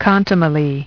Транскрипция и произношение слова "contumely" в британском и американском вариантах.